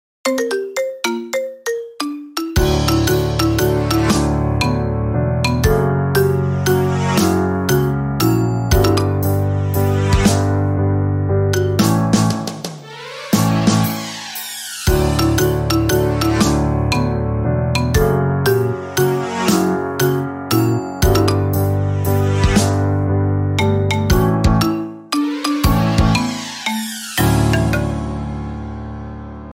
iphone marimba ringtone